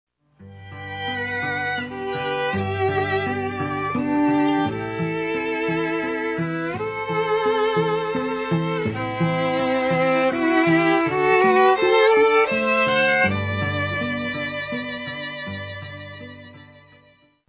Violinist
Wedding Ceremony and Prelude